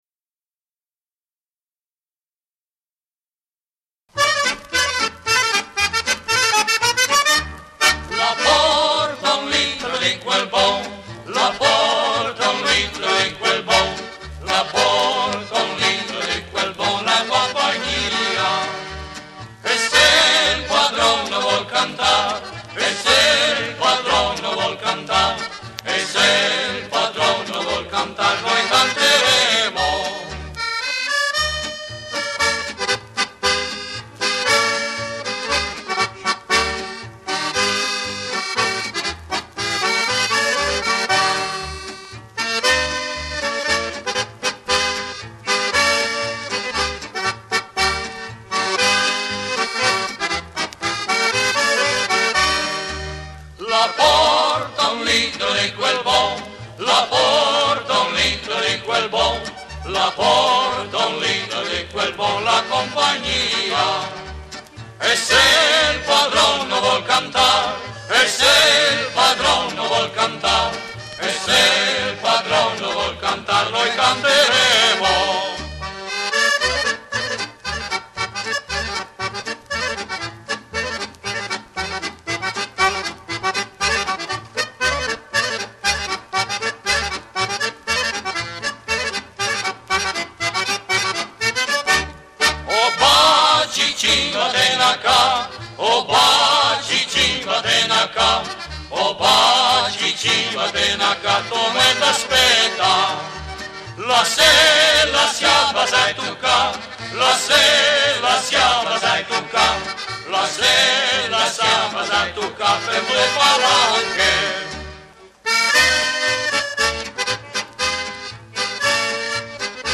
SING-A-LONG OLD ITALIAN POPULAR SONGS